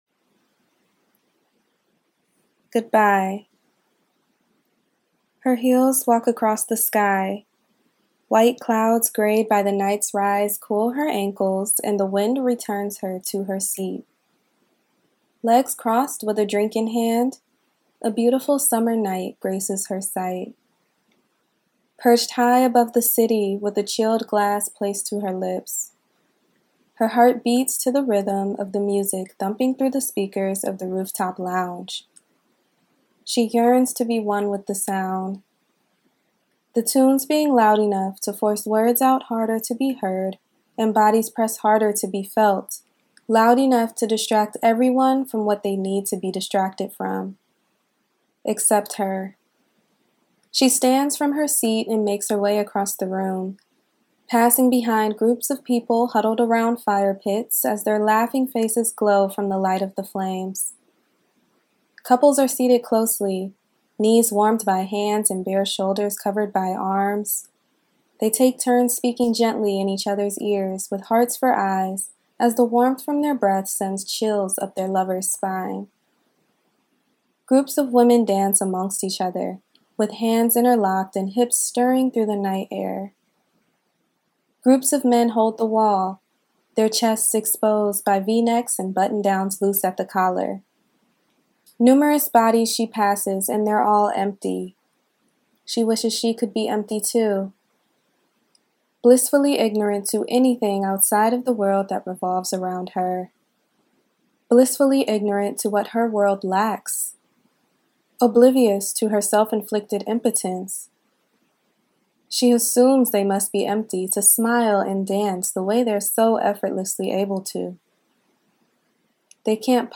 In celebration of the eBook for Goodbye, Hello being available today, below is an excerpt from the book.